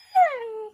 mob / wolf / whine.ogg
whine.ogg